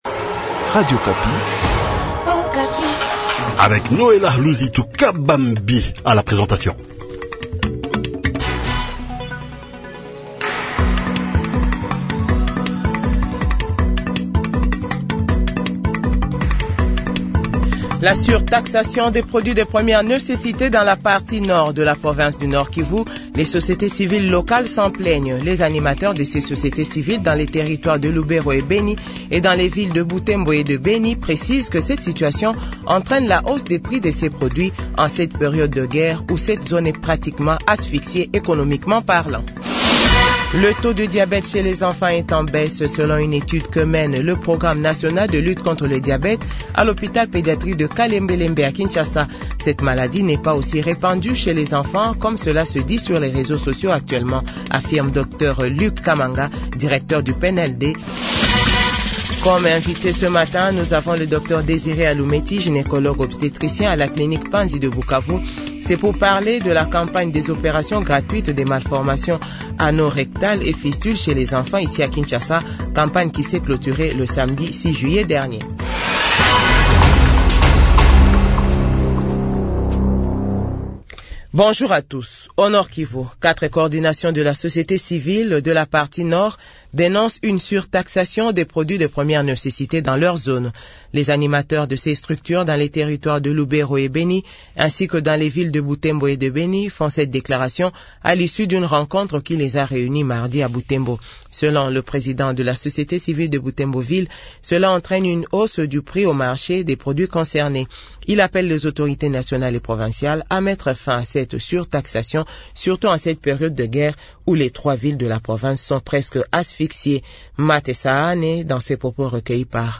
JOURNAL FRANCAIS 6H00 - 7H00